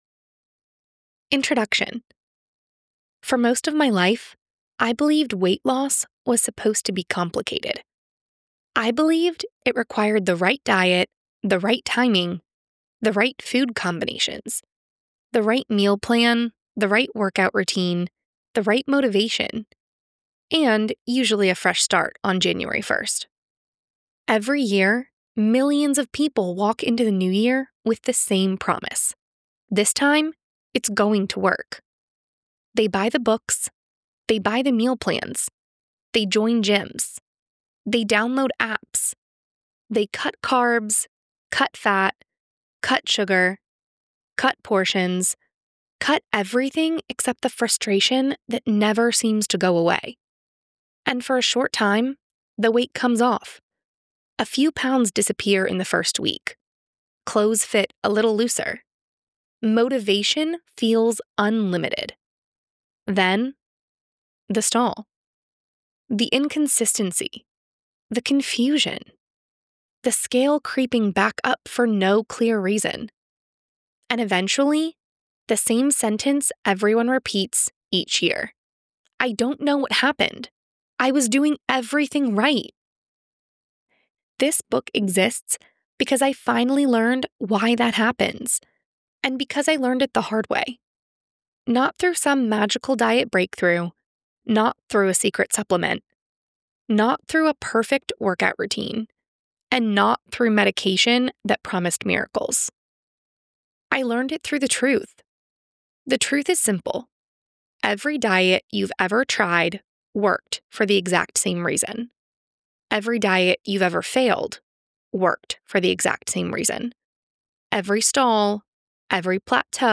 English - USA and Canada
Young Adult